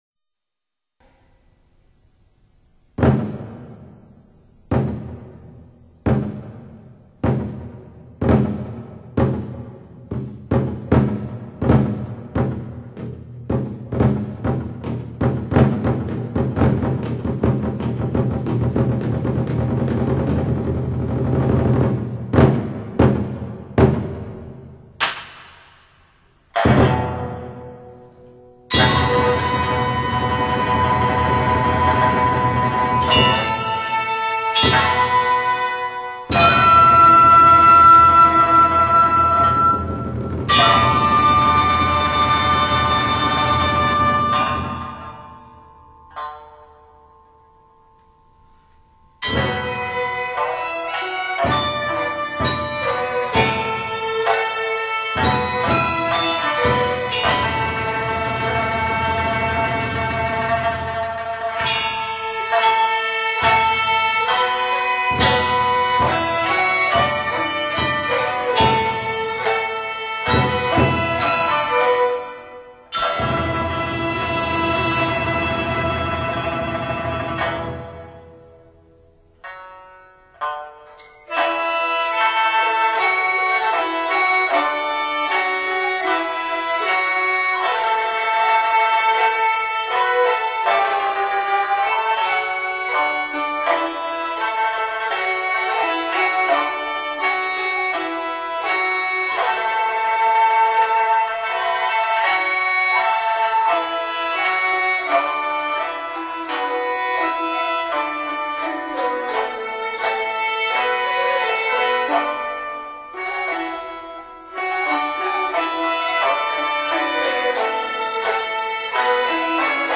大气恢宏的宫廷音乐
除了非同一般的典雅庄重还有非同一般的宫廷做派。
依仗工整而繁缛。